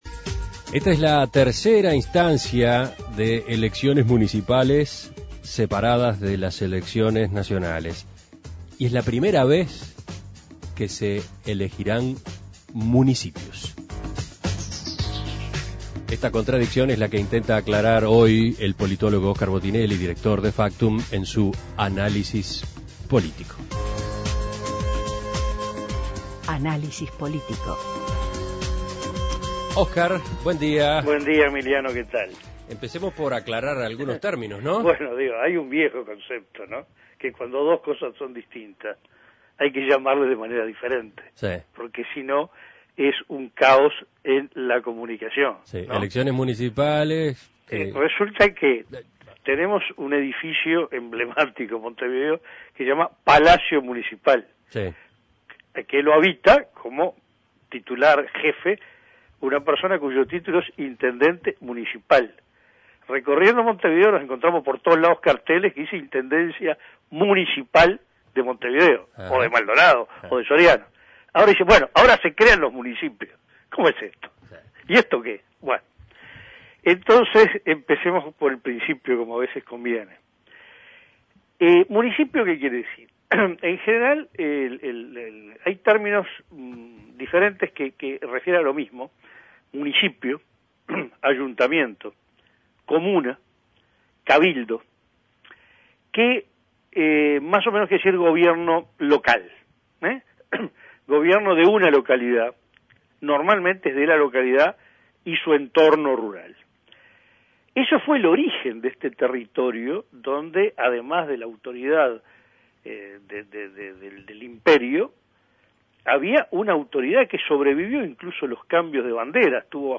Análisis Político La tercera instancia de elecciones municipales y la primera vez que se eligen municipios